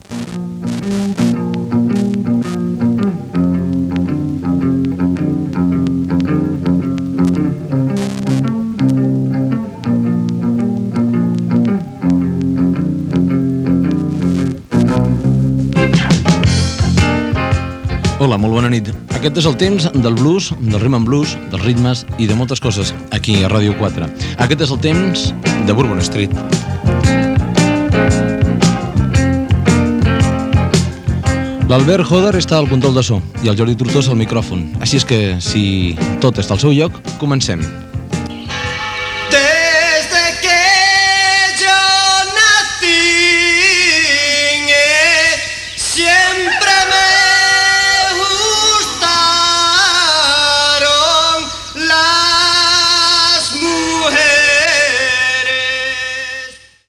Presentació inicial i primer tema musical.
Musical